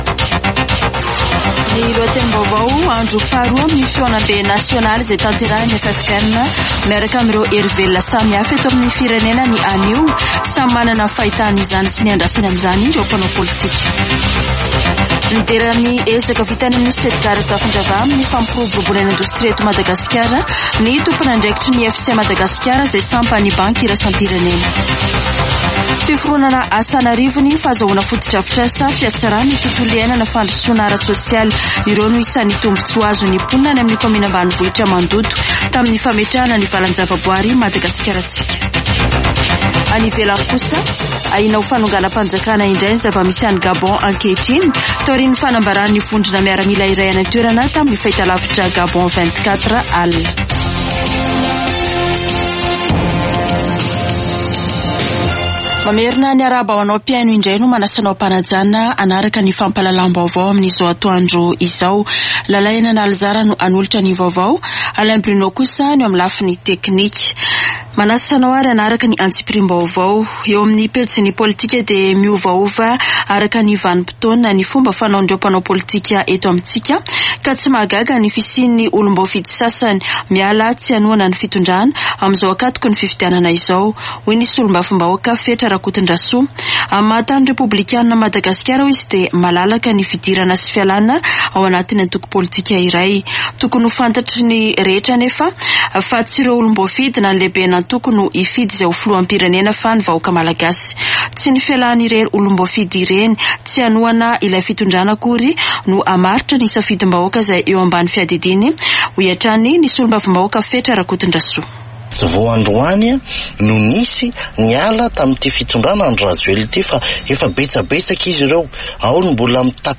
[Vaovao antoandro] Alarobia 30 aogositra 2023